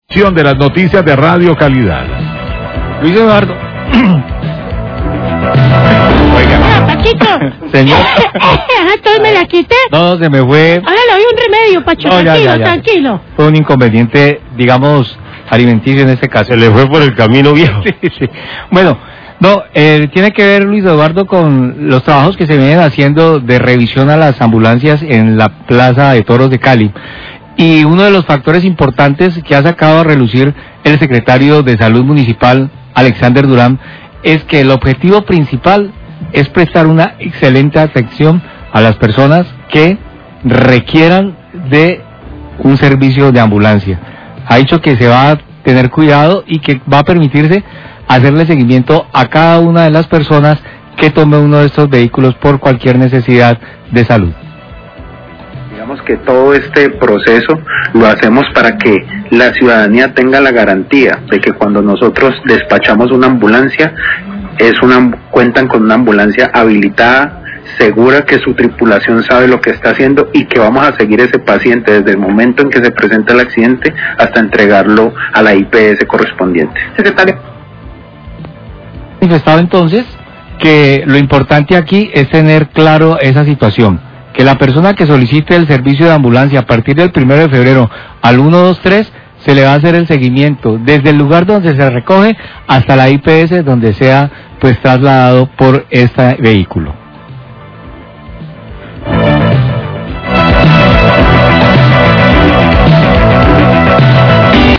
NOTICIAS DE CALIDAD
El secretario de Salud Alexander Durán habló sobre los avances en la revisión de las ambulancias que se realiza en los parqueaderos de la plaza de toros. Manifestó que lo importante del centro de despacho de ambulancias es garantizar a la ciudadanía que se va a realizar un control desde el momento que se recoje a un paciente hasta el momento en que es entregado a la IPS.